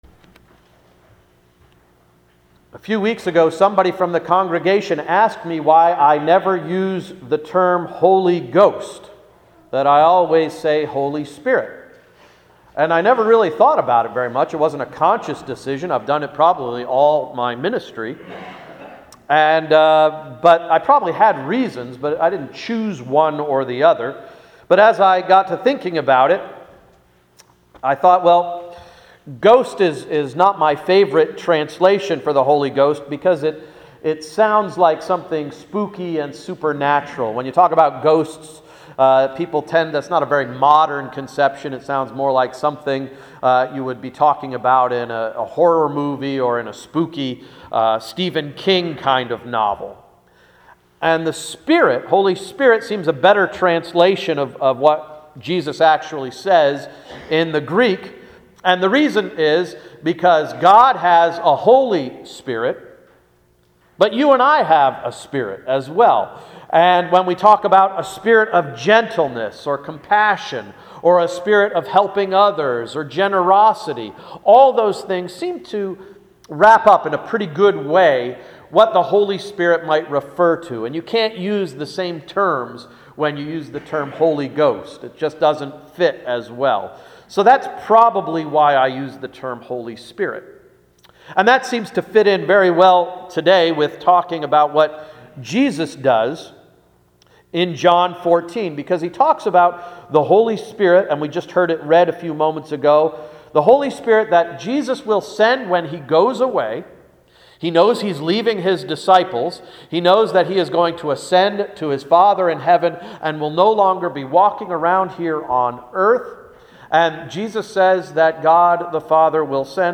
Sermon of May 5, 2013–“Temple of the Holy Ghost”